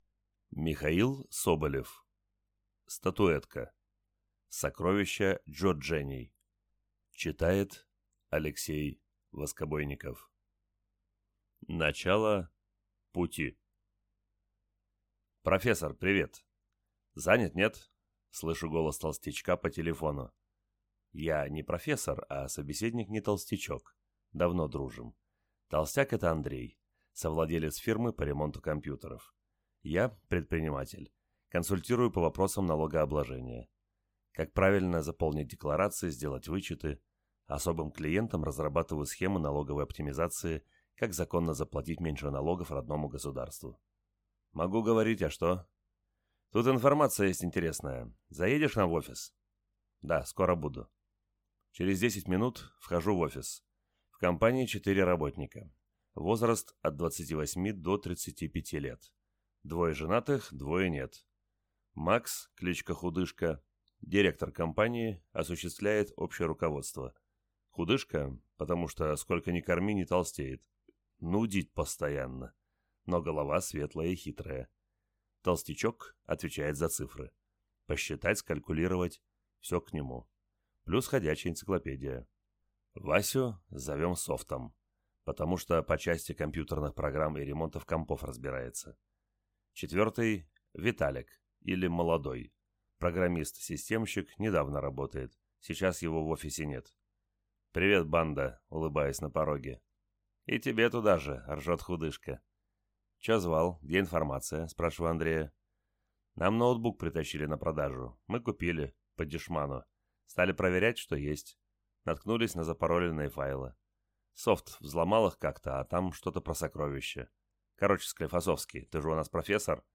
Аудиокнига Статуэтка. Сокровища чжурчжэней | Библиотека аудиокниг